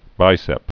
(bīsĕp)